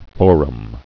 (Pronunciation Key)fo·rum Listen: [ fôrm, fr- ]